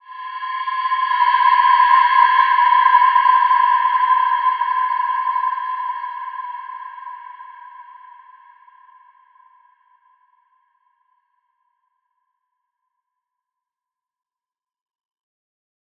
Wide-Dimension-C5-f.wav